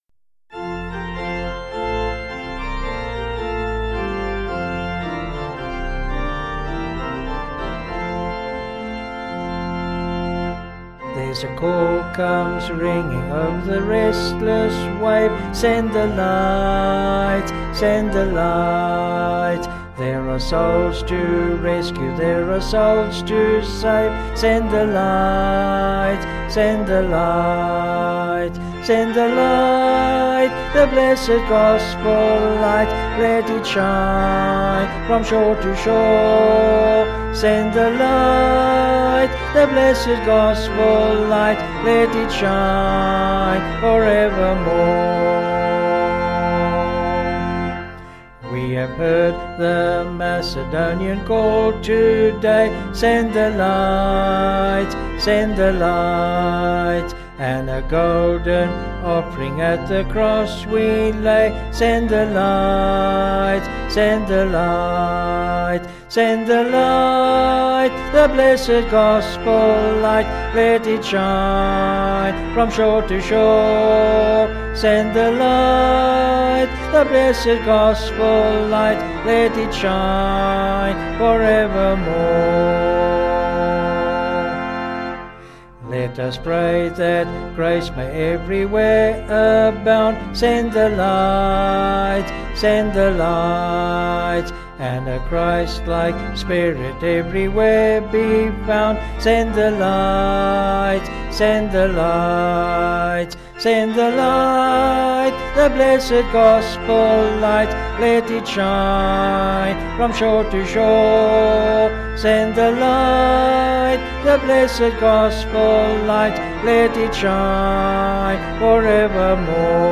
Vocals and Organ   264kb Sung Lyrics